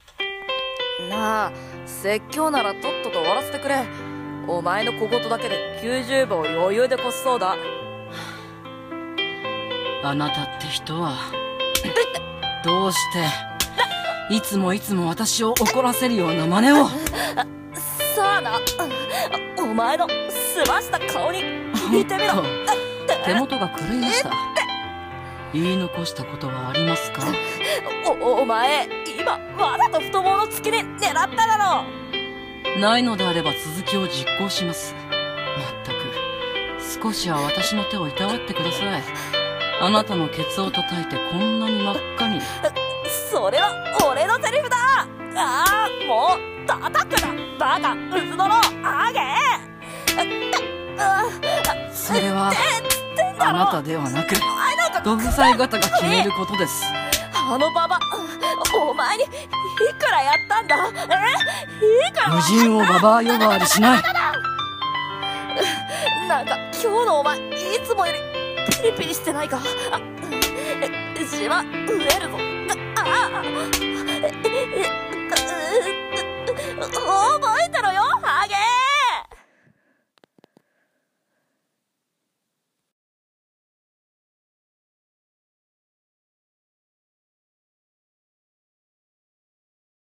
1話 (1/3) 放蕩息子の躾け方【BL声劇台本】